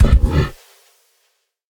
Minecraft Version Minecraft Version snapshot Latest Release | Latest Snapshot snapshot / assets / minecraft / sounds / mob / camel / dash3.ogg Compare With Compare With Latest Release | Latest Snapshot